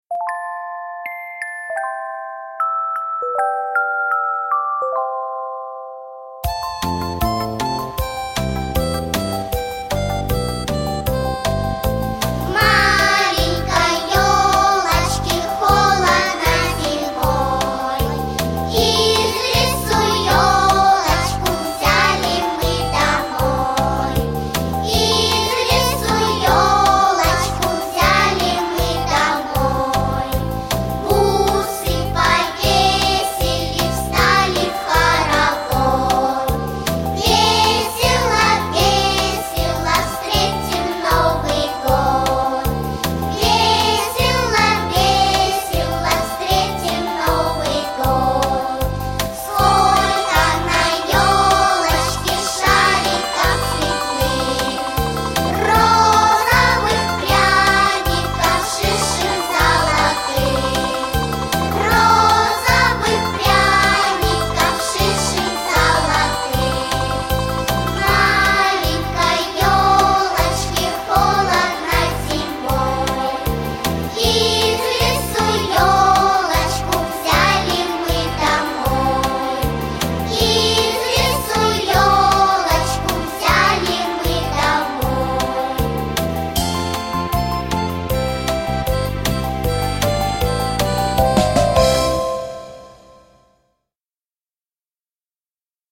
детской песни
Скачать или прослушать фонограмму (плюс) песни